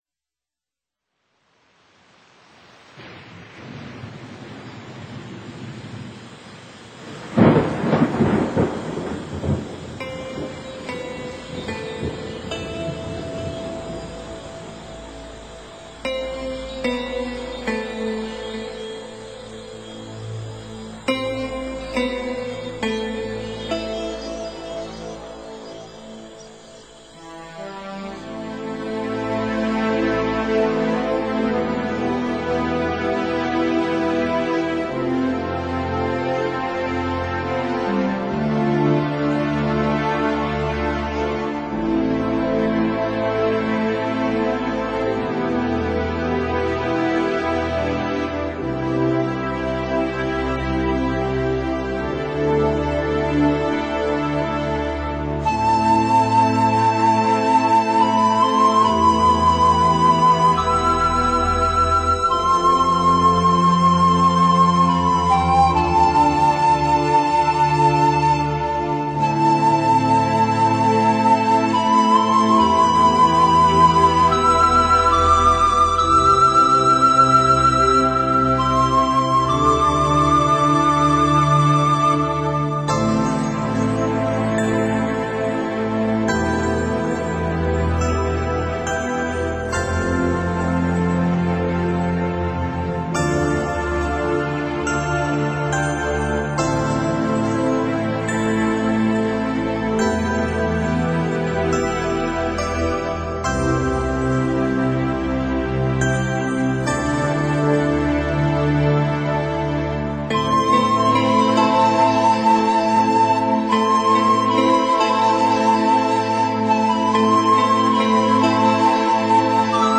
心情便随着缓缓的河水流动！